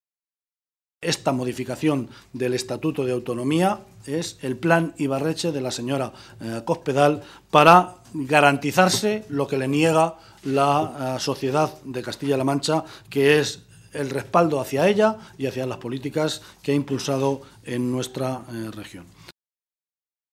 José Luis Martínez Guijarro, portavoz del Grupo Parlametario Socialista
Cortes de audio de la rueda de prensa